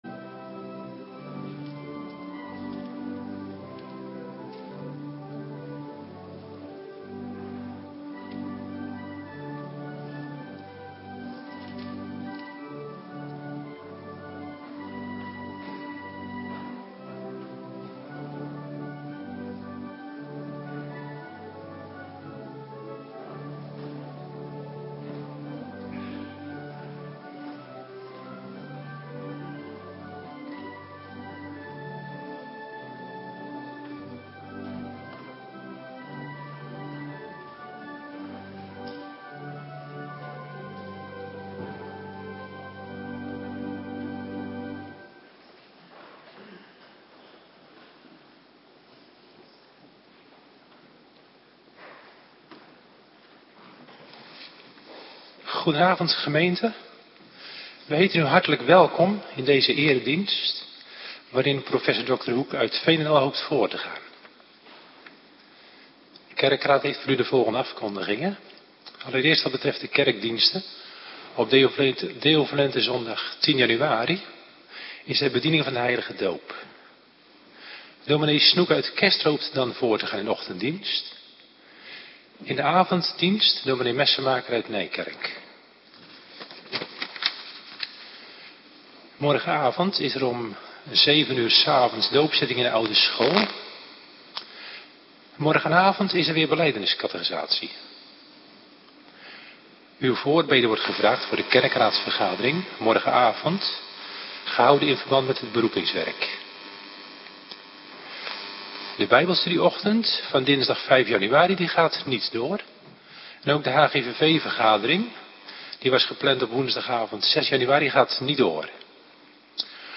Avonddienst - Cluster 1